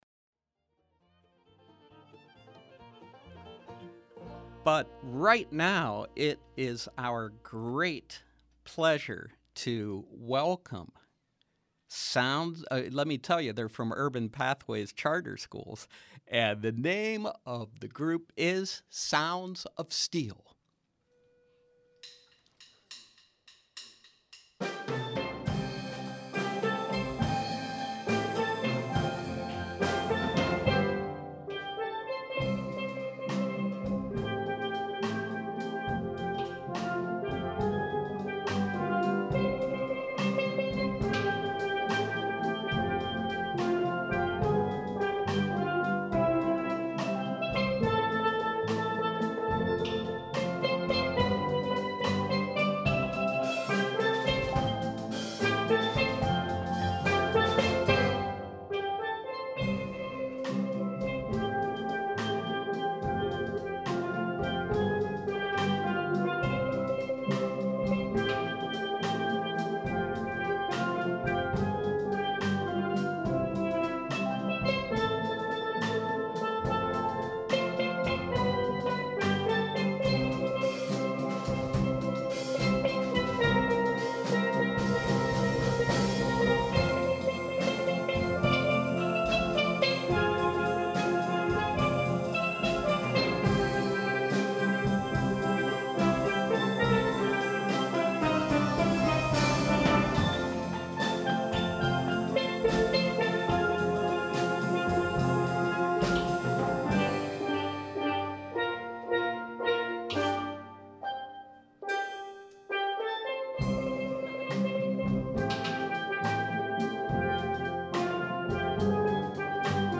From 6/8/13: Sixteen students from Urban Pathways Charter School Sounds of Steel steelpan/steel drum ensemble